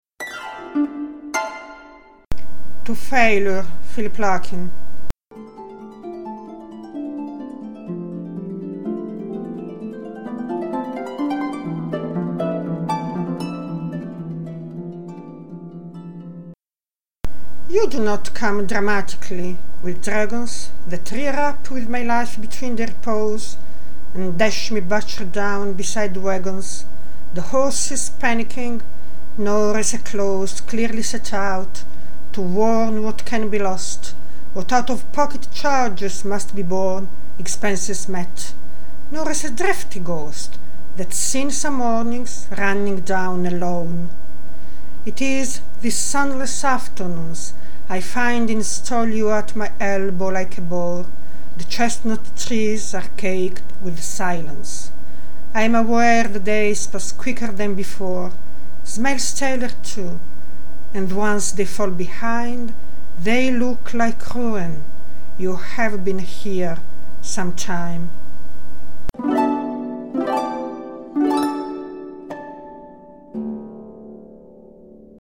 Suoni poetici » Poesie recitate da artisti